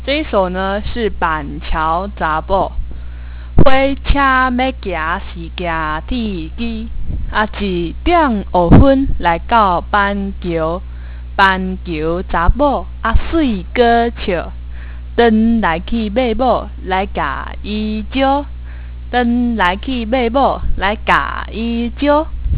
傳統念謠